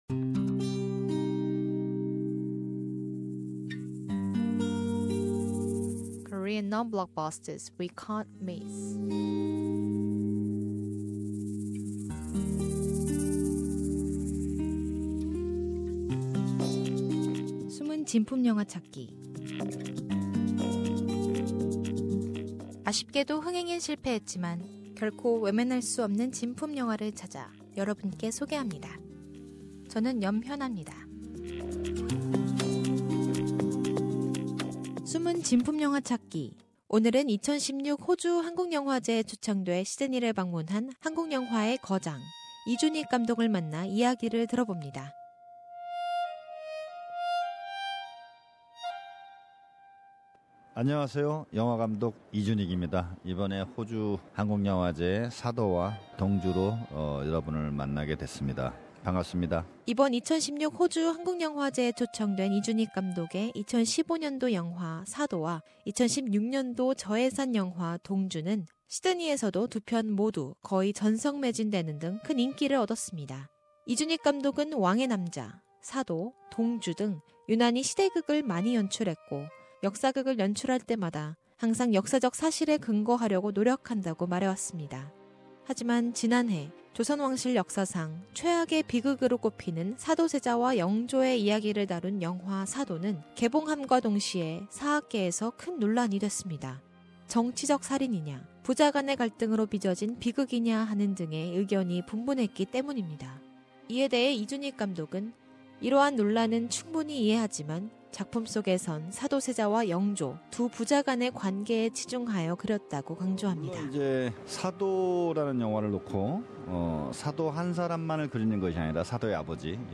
Korean film director Lee Joon-ik